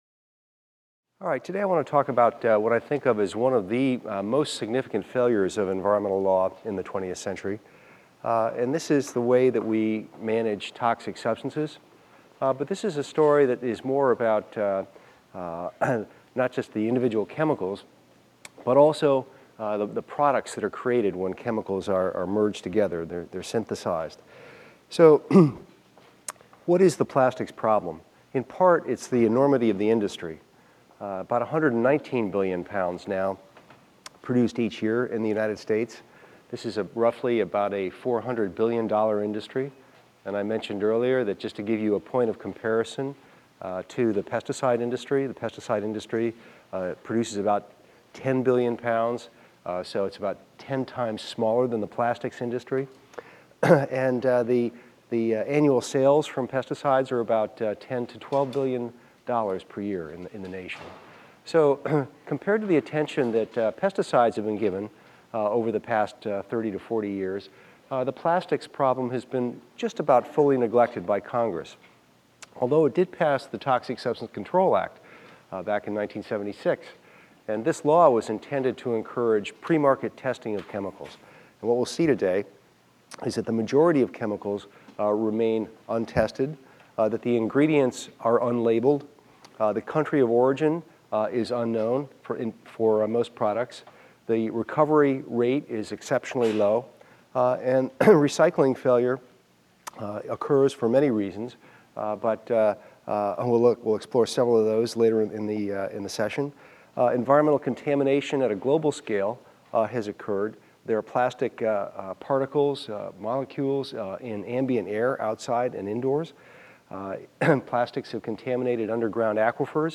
EVST 255 - Lecture 14 - The Quiet Revolution in Plastics | Open Yale Courses